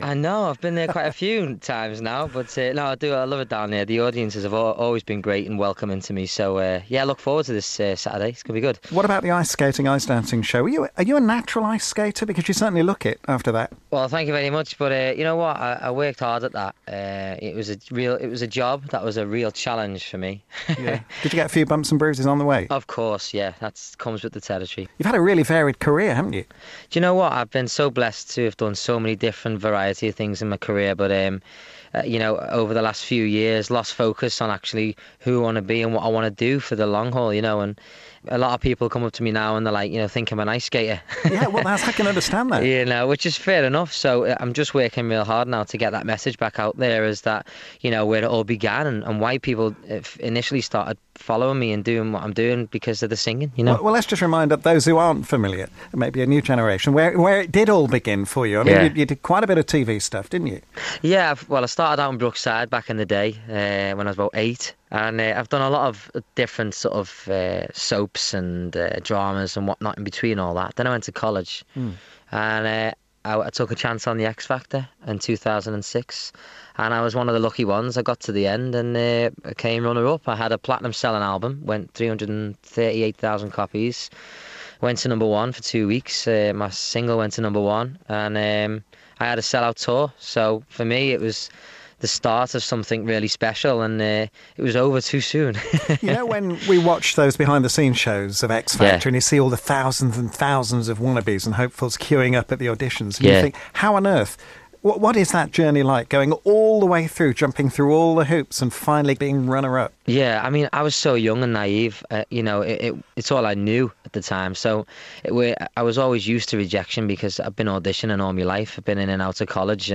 Ray chats ahead of his visit to Skegness to switch on the illuminations. He's also visiting Stamford Corn Exchange on Saturday September 20th to sing material from his new album.